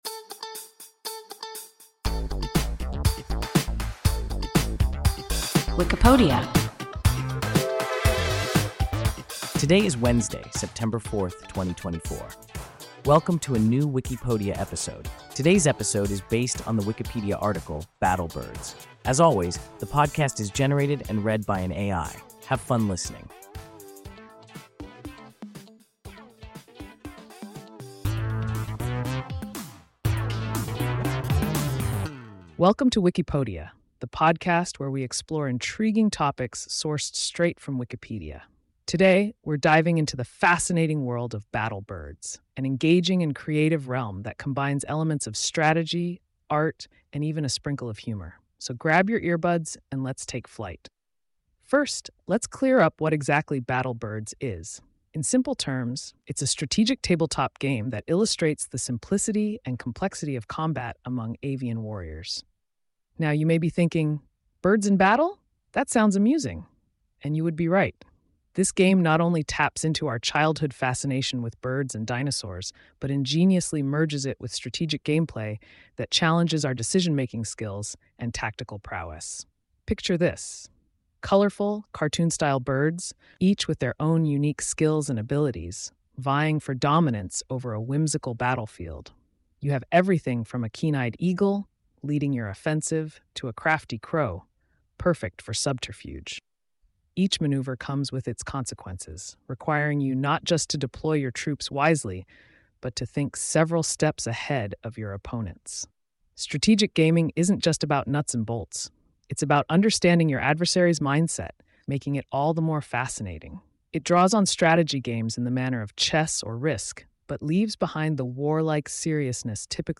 Battle Birds – WIKIPODIA – ein KI Podcast